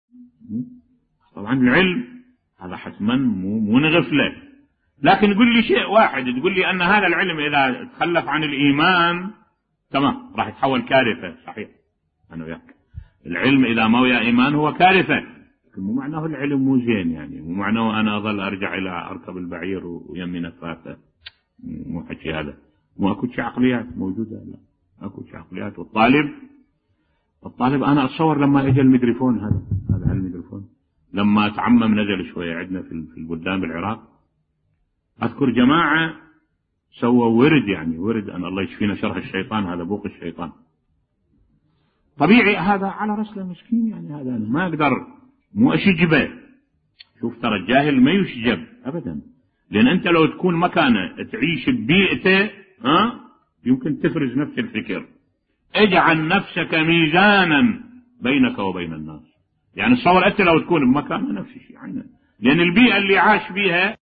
ملف صوتی تفاعل اهل العراق مع المكيرفون في بدايته بصوت الشيخ الدكتور أحمد الوائلي